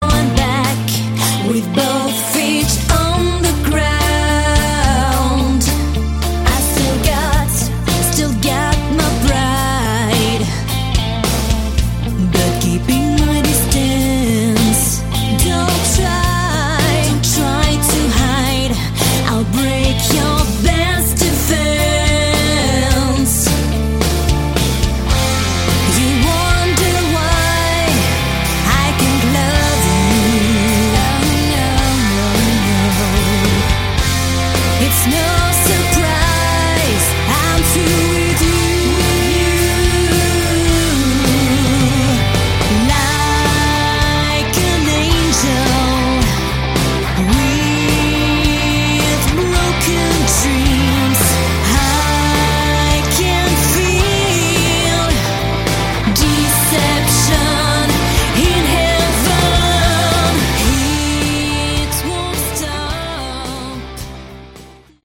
Category: Melodic Rock
lead and backing vocals